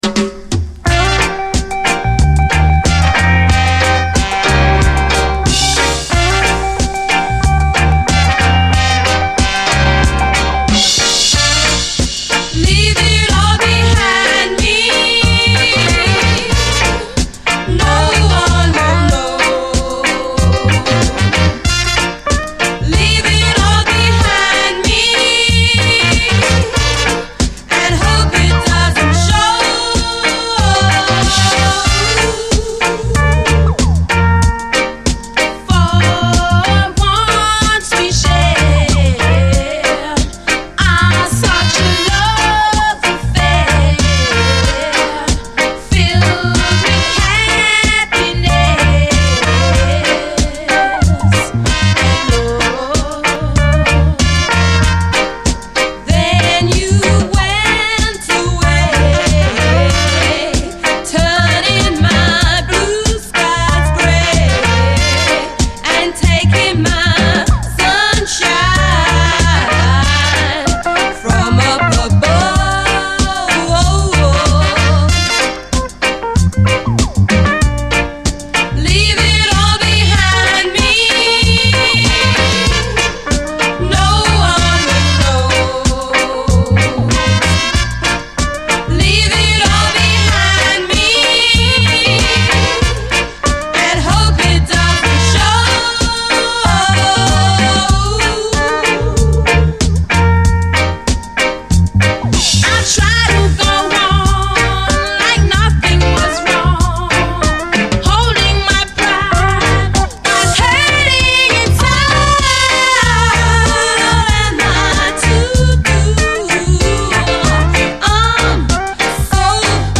REGGAE
後半にプレスミスありその箇所でプチノイズ２回有/　レア＆キラーUKラヴァーズ！